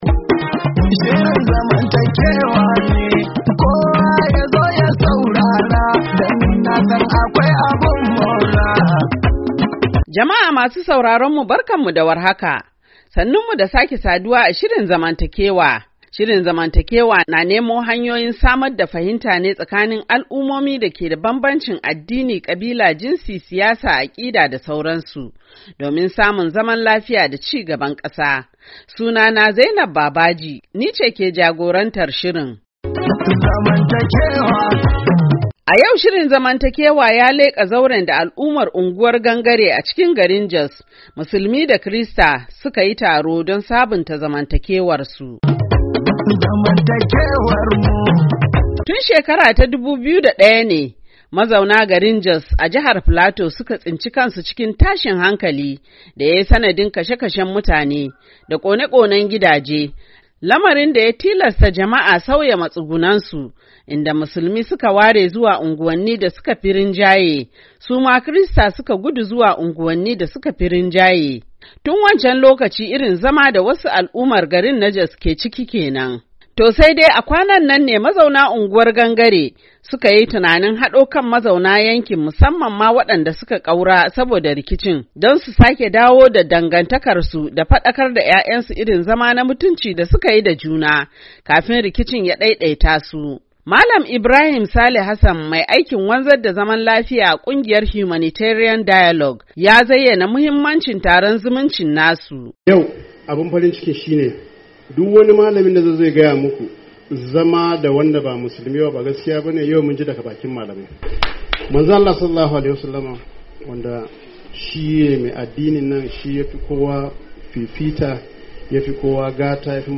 A shirin Zamantakewa na wannan makon, mun shiga zauren taron zumunci ne da al'ummar unguwar Gangare a birnin Jos na jihar Filato suka shirya don dawo da zamantakewarsu, kara samar da fahimtar juna da lalubo hanyoyin warware wasu matsaloli bayan da rikici ya daidaita su.